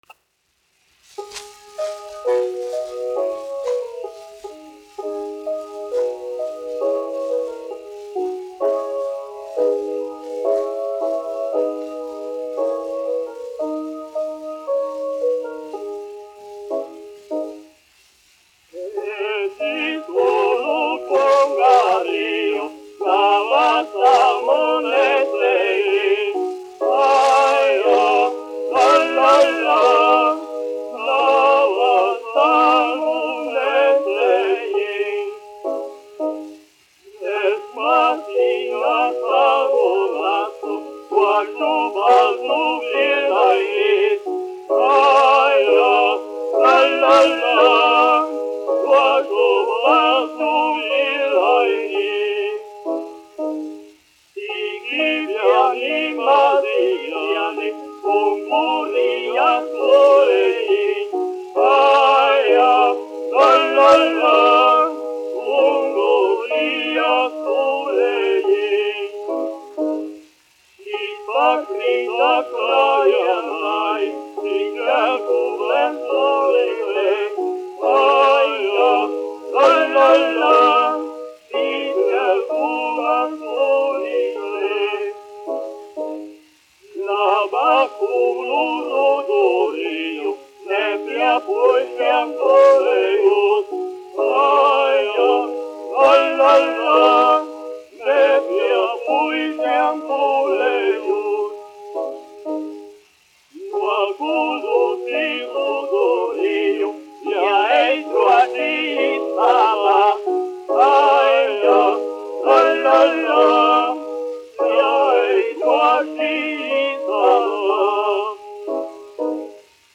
Jāzeps Vītols, 1863-1948, aranžētājs
1 skpl. : analogs, 78 apgr/min, mono ; 25 cm
Latviešu tautasdziesmas
Vokālie dueti
Rīgas Jaunā latviešu teātra dziedātāji